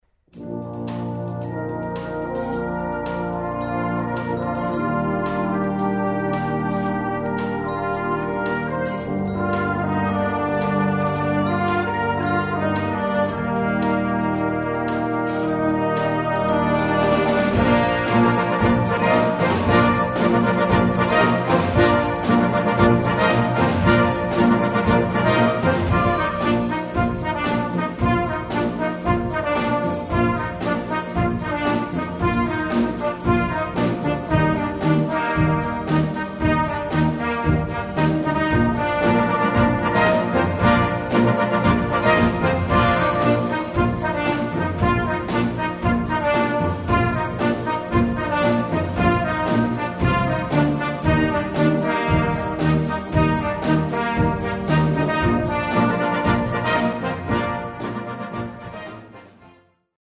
Gattung: Popmusik
A4 Besetzung: Blasorchester Zu hören auf